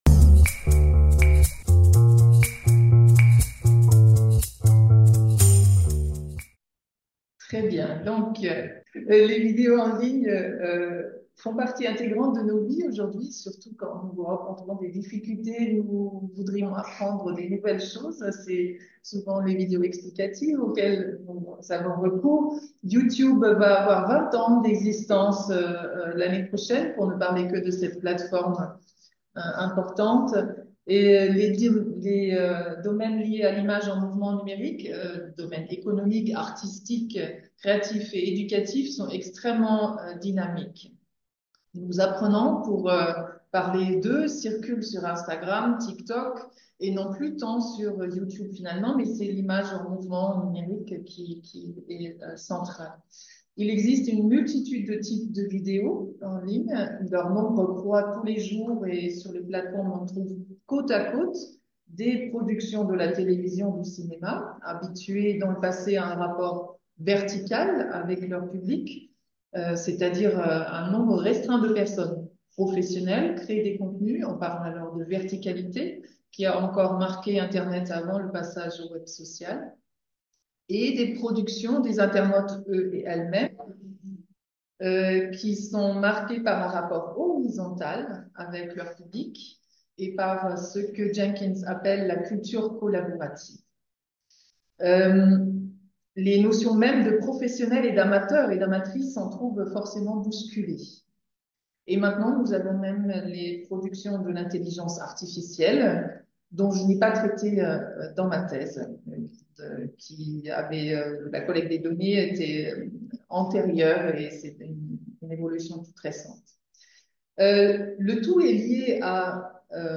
[Conférence]